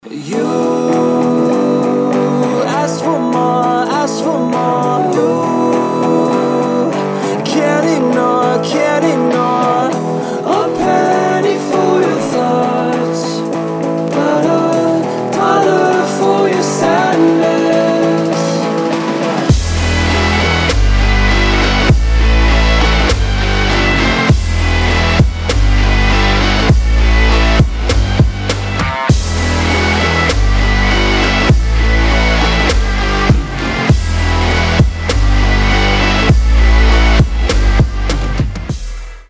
• Качество: 320, Stereo
Electronic
нарастающие
Trap
электрогитара
future bass